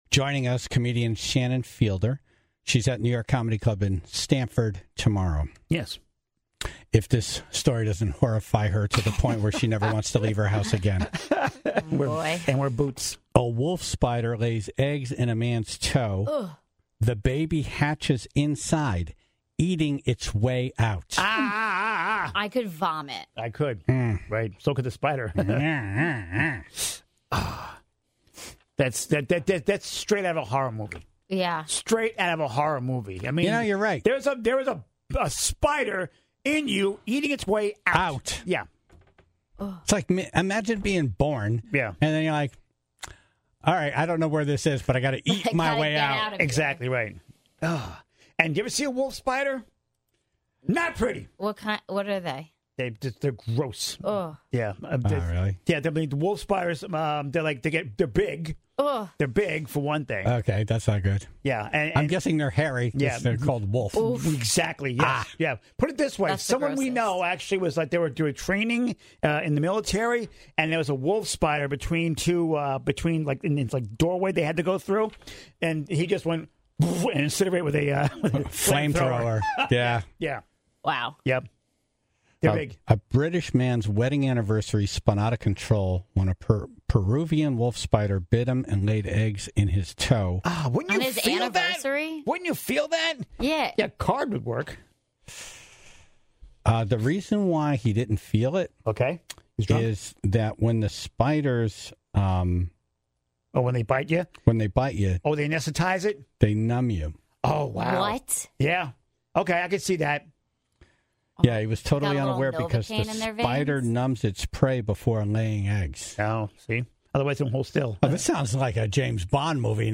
Then, the Tribe started to call in their own stories and encounters with spiders.